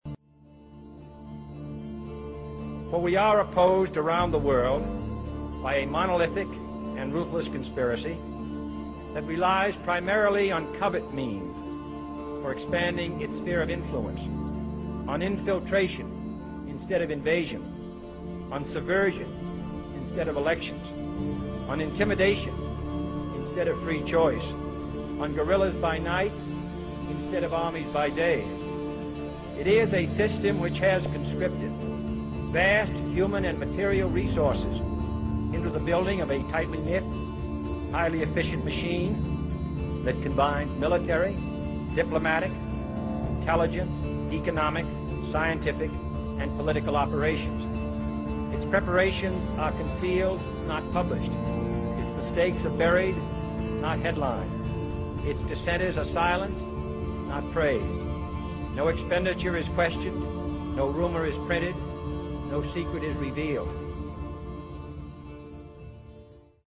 Listen to the following audio clip by John F Kennedy, the last truly elected President of the United States.